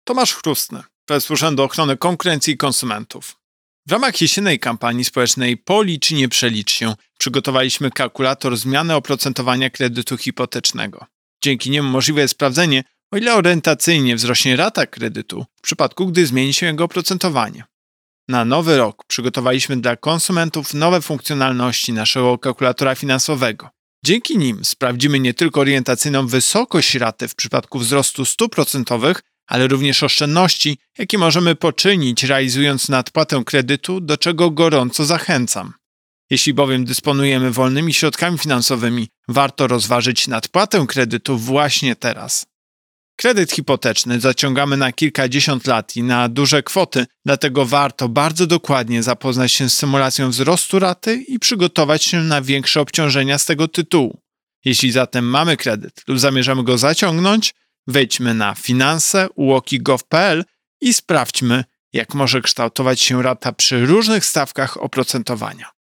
Wypowiedź Prezesa UOKiK Tomasza Chróstnego z 5 stycznia 2022 r..mp3